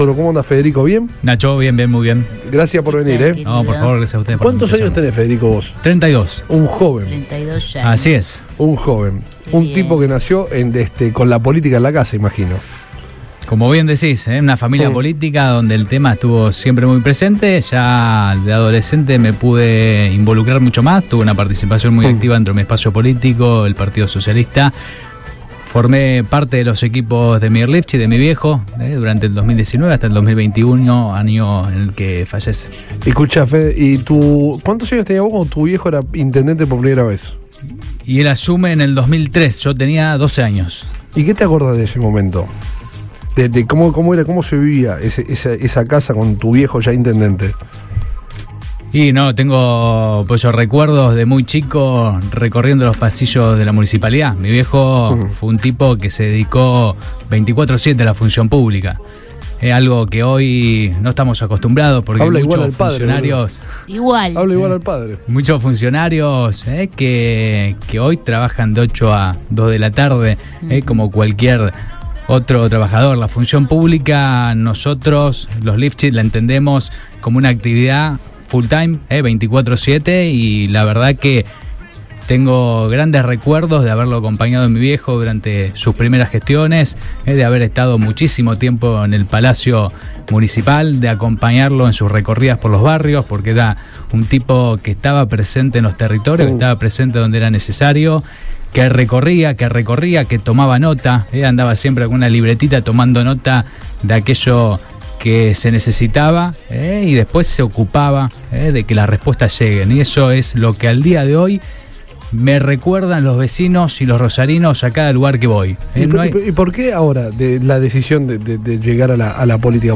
estuvo presente en Todo Pasa, en Radio Boing y analizó como ve a la ciudad